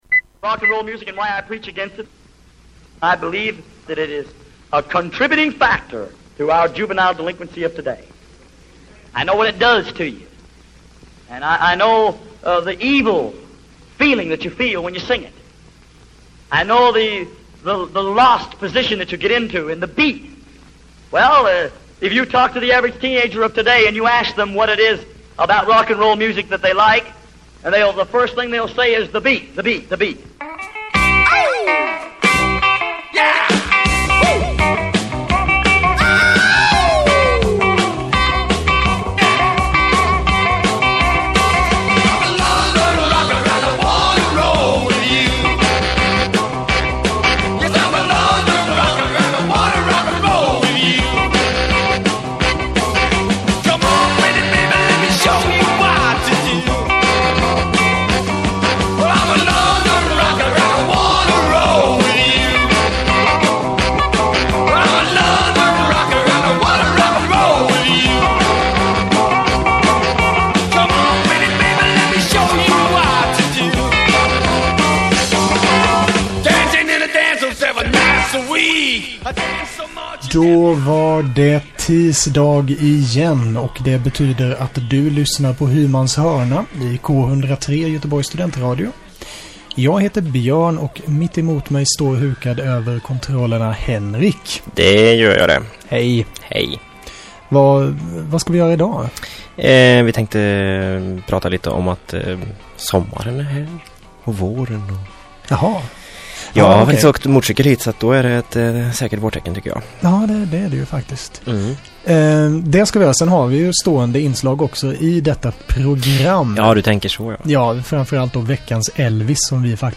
Hymans H�rna handlar om rock n' roll, hela v�gen fr�n country och blues fram till punk och h�rdrock. Det blir sprillans nytt s�v�l som knastrande gammalt, varvat med intervjuer och reportage fr�n G�teborgs musikv�rld.